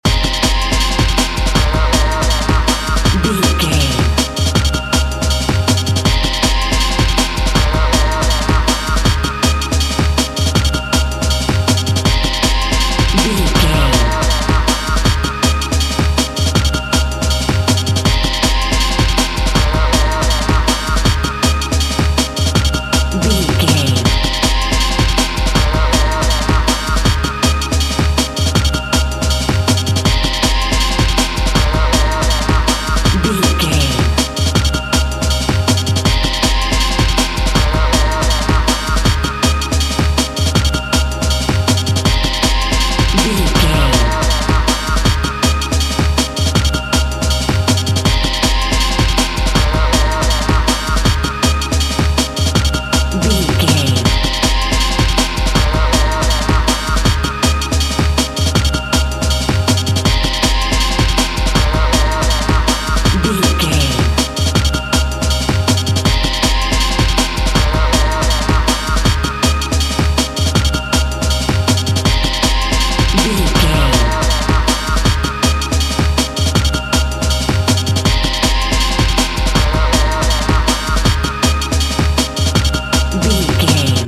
The Drums and Bass.
B♭
Fast
hypnotic
industrial
driving
energetic
frantic
drum machine
synthesiser
Drum and bass
break beat
electronic
sub bass
synth lead